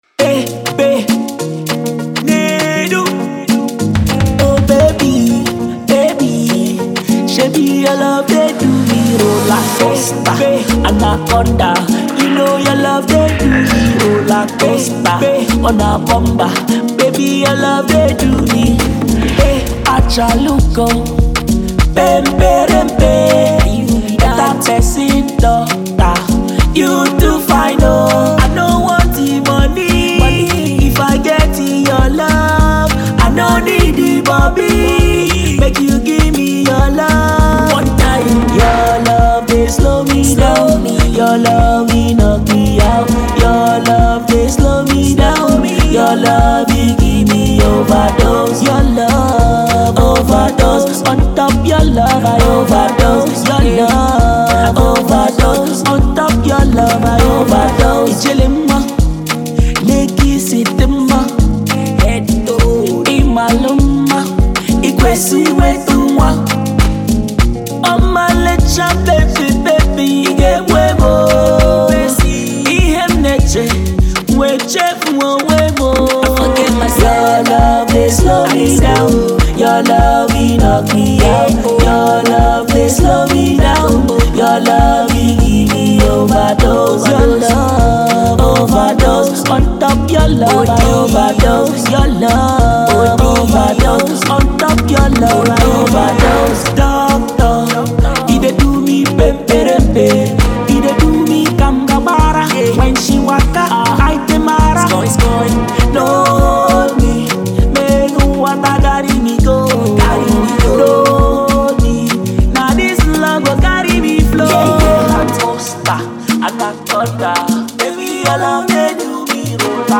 an Atlanta-based Afrobeat extraordinaire